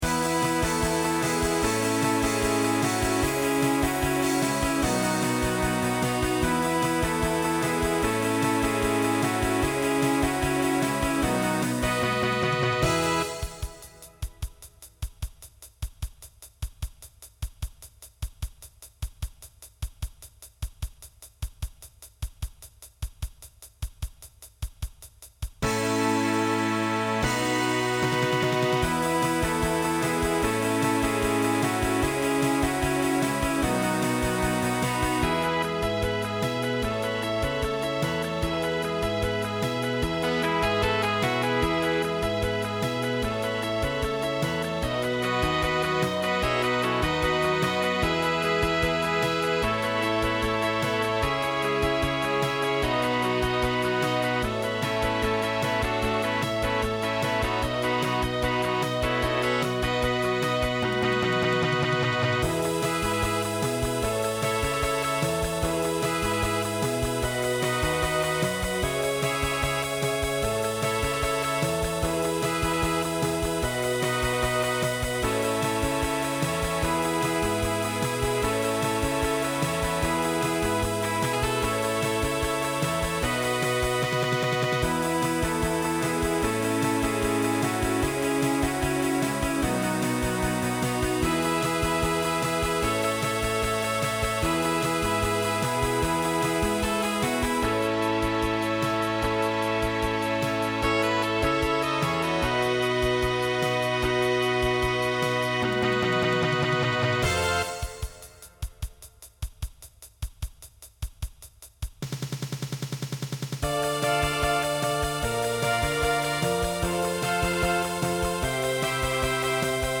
Voicing SATB Instrumental combo Genre Country